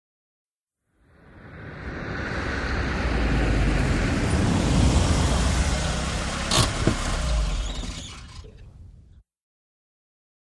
VEHICLES ASTON MONTEGO: EXT: Approach, switch off, slow, with hand brake.